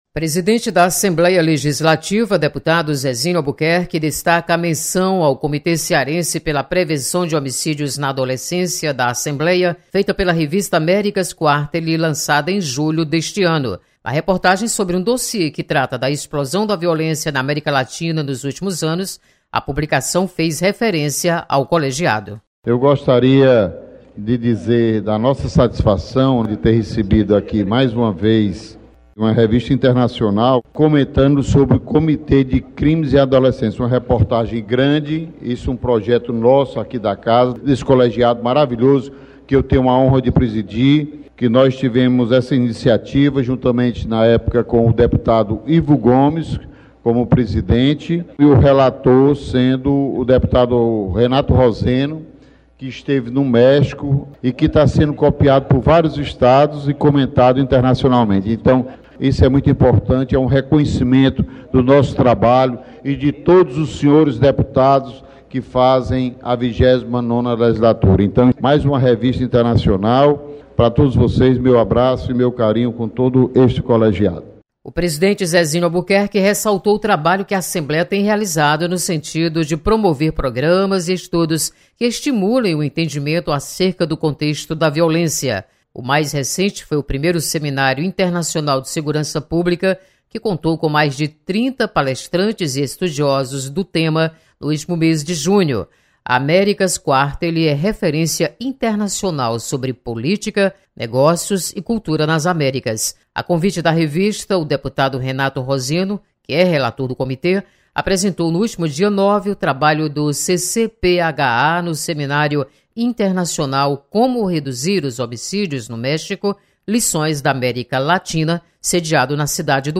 Deputado Zezinho Albuquerque enaltece trabalho do Comitê Cearense pela Prevenção de Homicídios na Adolescência. Repórter